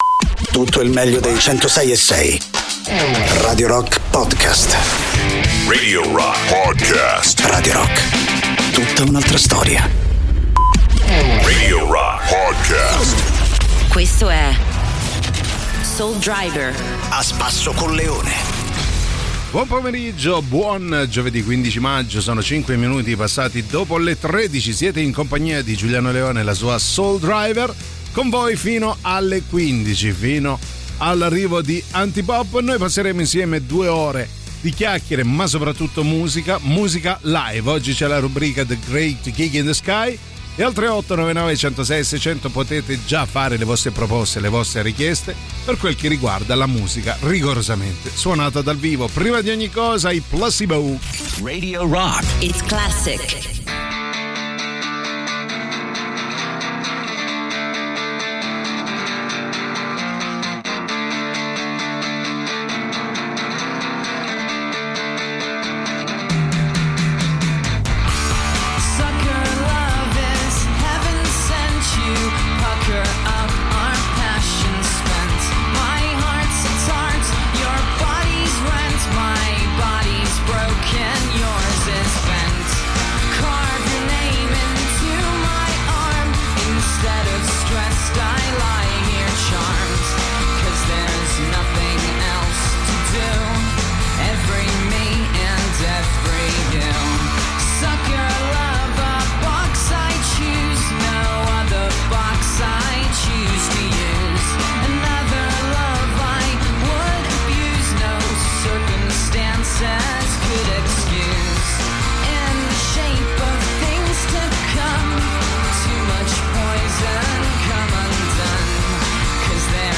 in diretta dal lunedì al venerdì, dalle 13 alle 15, con “Soul Driver” sui 106.6 di Radio Rock.